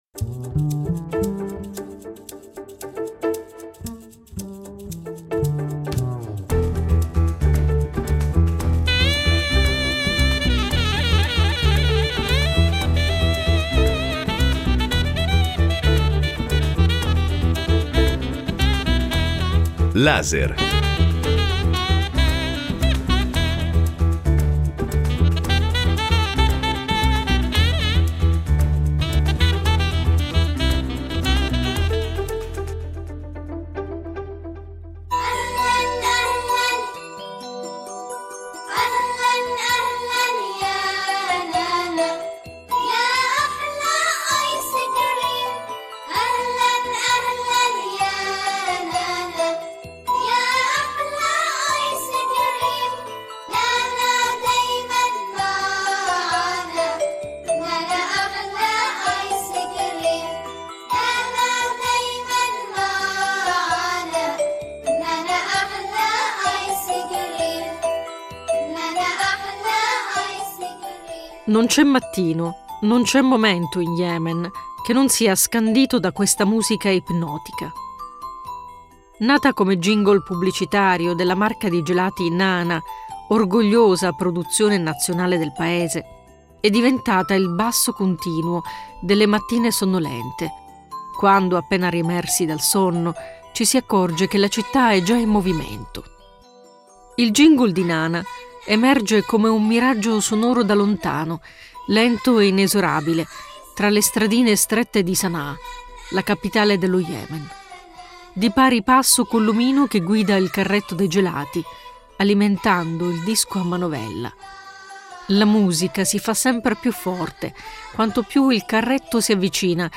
un documentario